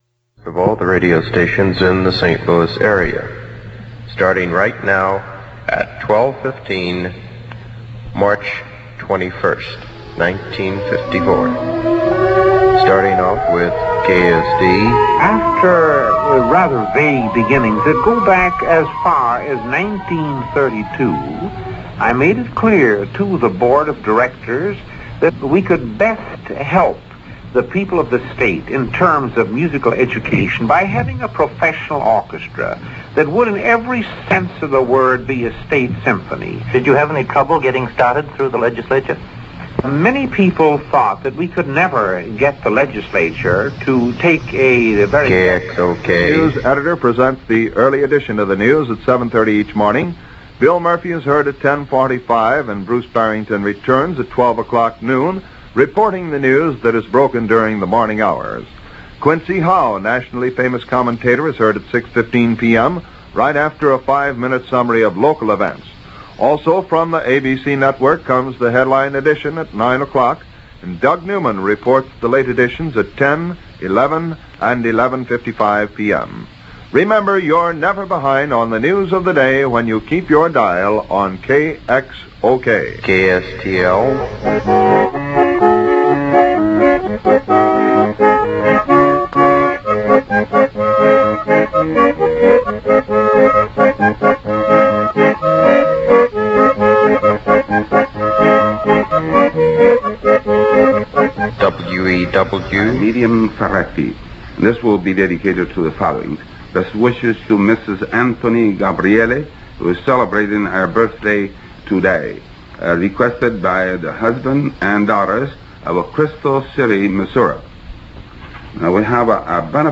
AM Dial aircheck · St. Louis Media History Archive
Original Format aircheck